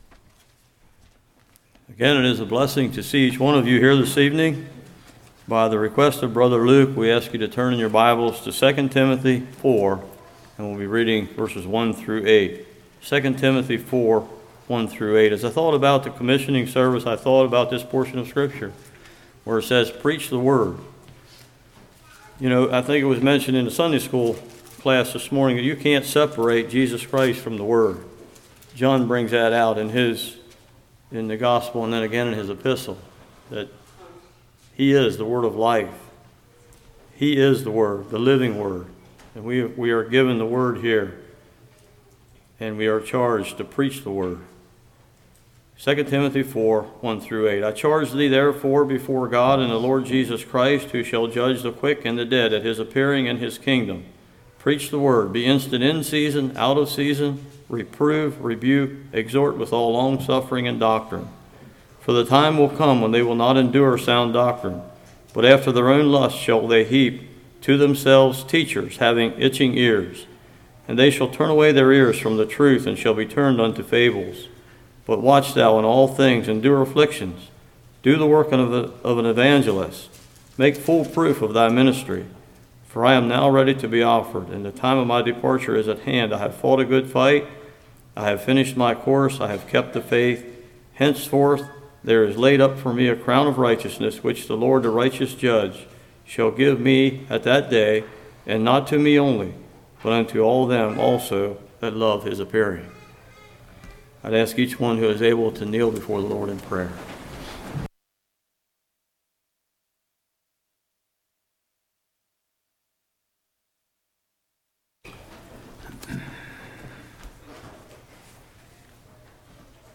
Service Type: Commissioning Service